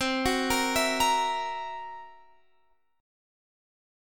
Listen to C7b5 strummed